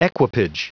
Prononciation du mot equipage en anglais (fichier audio)
Prononciation du mot : equipage